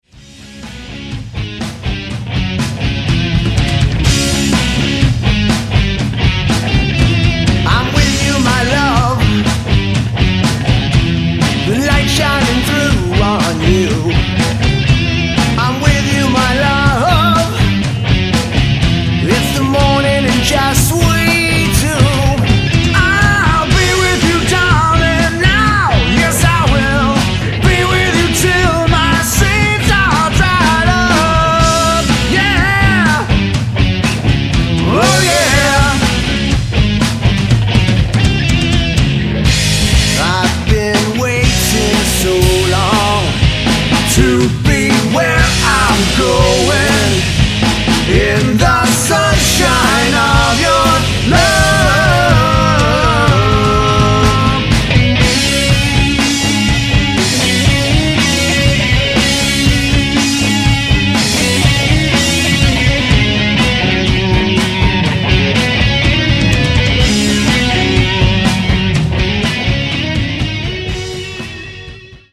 *** Audio Samples (covers): ***